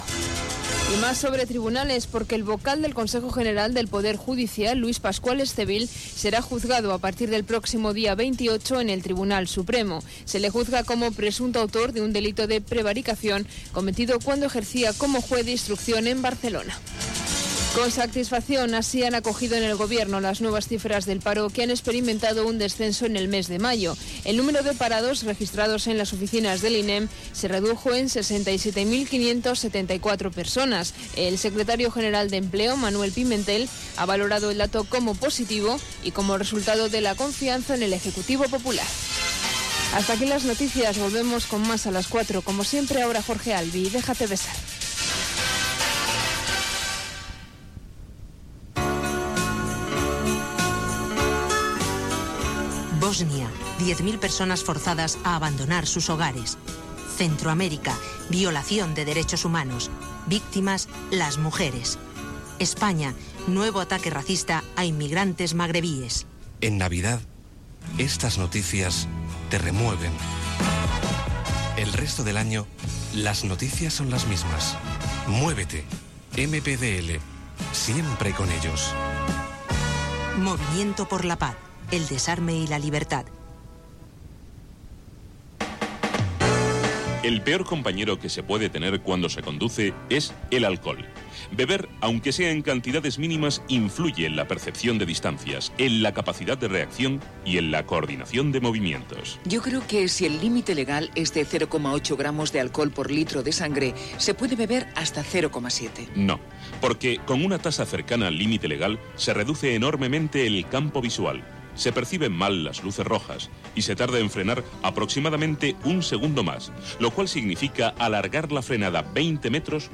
Final del butlletí de notícies: el cas Luis Pascual Estivill i dades de l'atur. Publicitat, promoció de "Matinal Romeo Show" a Onda 10
FM